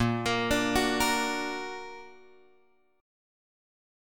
A# Augmented
A#+ chord {6 5 8 7 7 x} chord